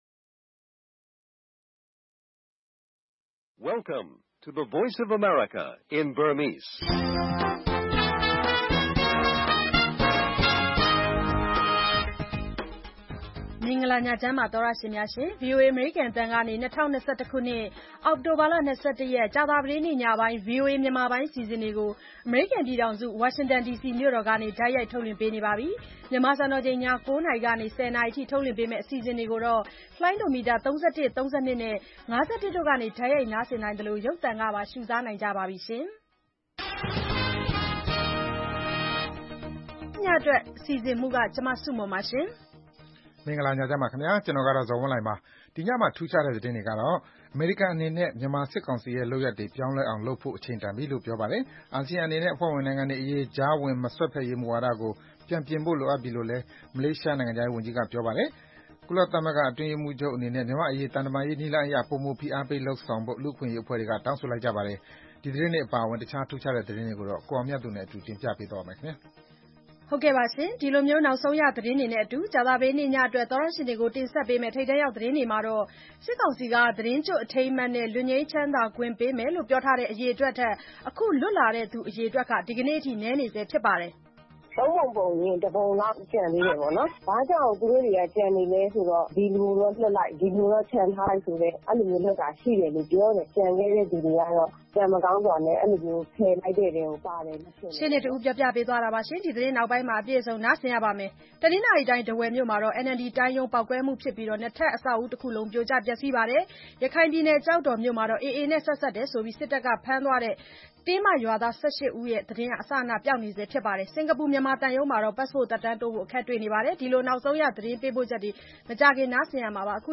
ဗွီအိုအေ ကြာသပတေးည ၉း၀၀-၁၀း၀၀ နာရီ ရေဒီယို/ရုပ်သံလွှင့် အစီအစဉ်